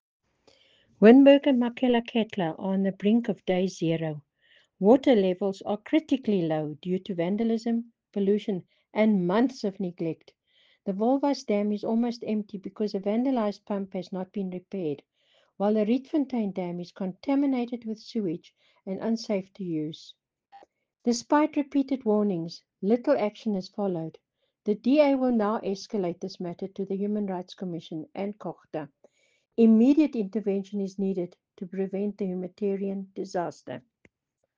Afrikaans soundbites by Cllr Brunhilde Rossouw and